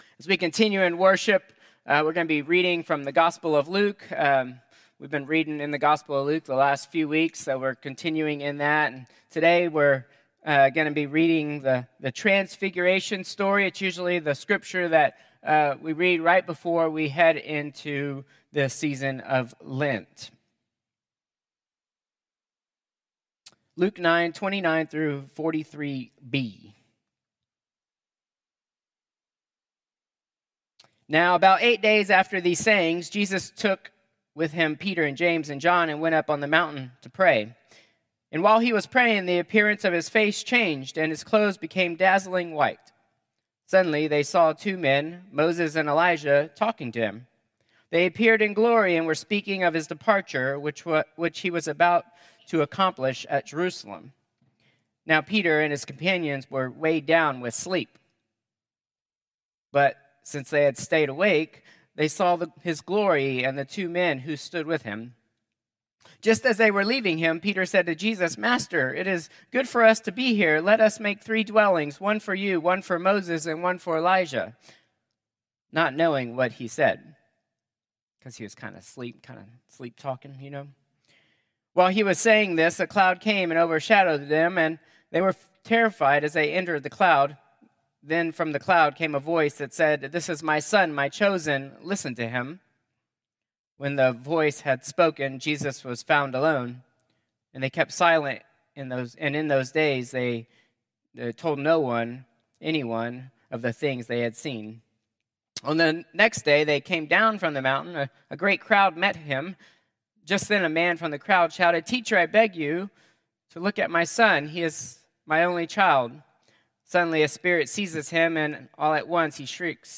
Contemporary Service 3/2/2025